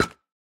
Minecraft Version Minecraft Version snapshot Latest Release | Latest Snapshot snapshot / assets / minecraft / sounds / block / decorated_pot / step5.ogg Compare With Compare With Latest Release | Latest Snapshot
step5.ogg